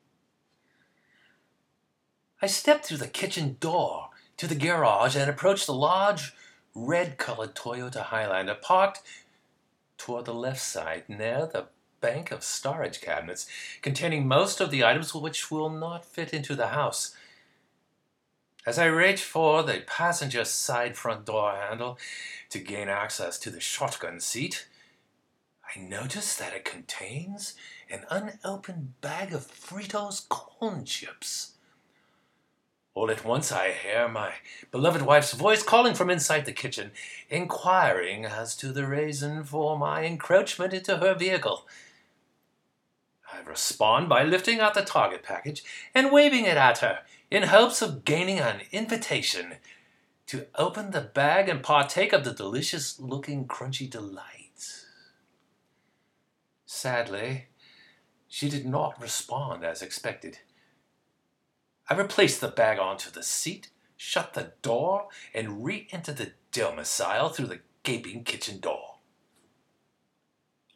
In the movie, I am required to speak with a British accent.
In a British accent (sort of).
british-test-5.m4a